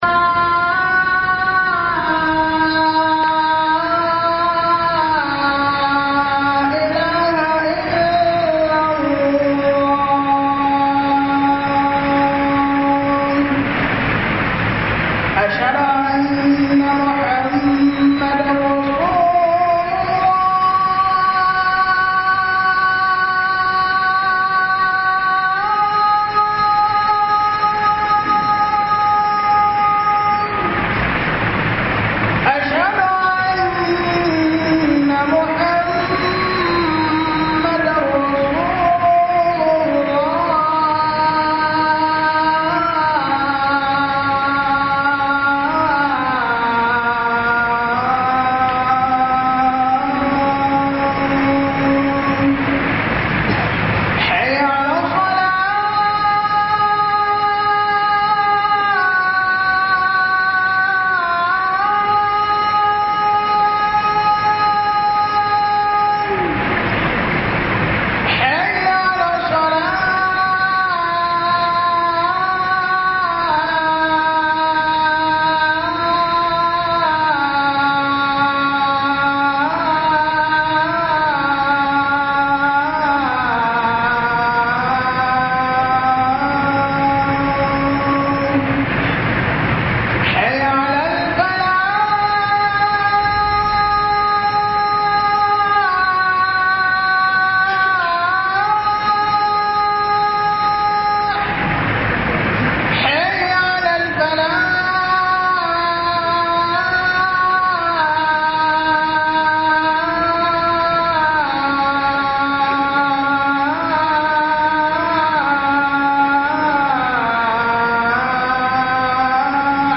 HUDUBA-TAUSAYI